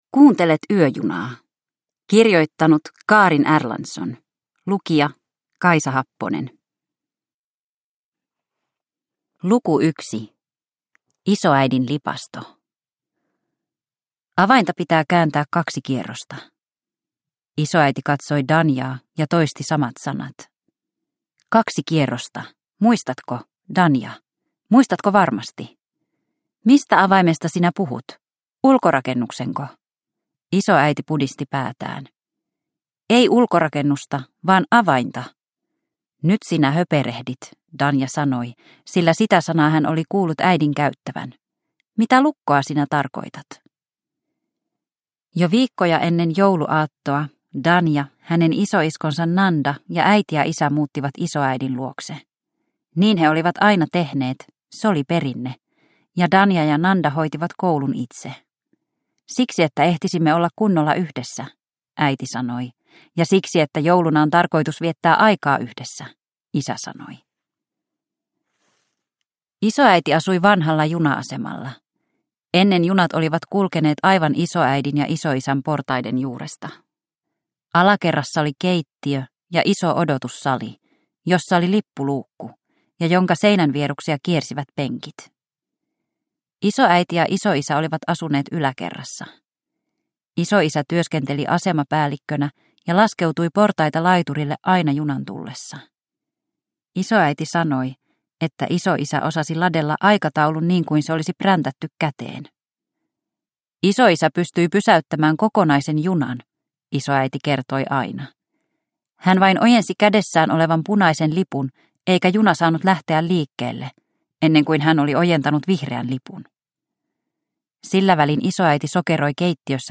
Yöjuna – Ljudbok